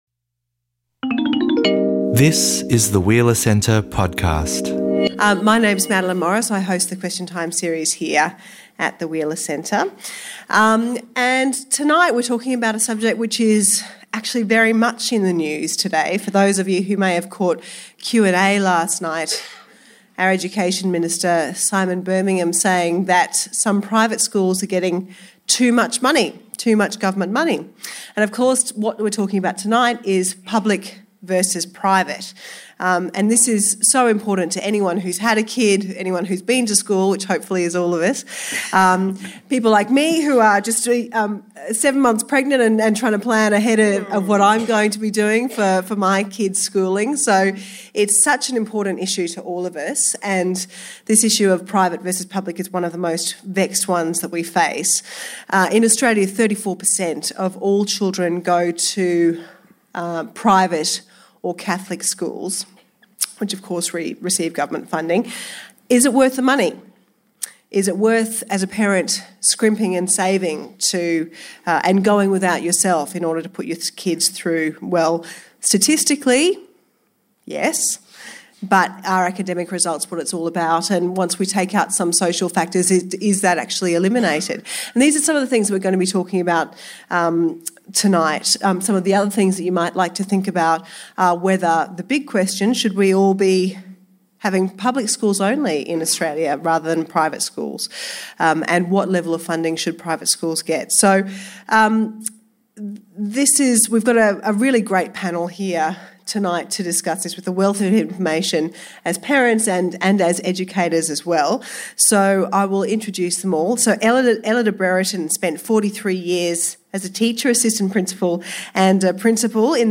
We unpack these questions, and more, in a full hour of audience Q&A.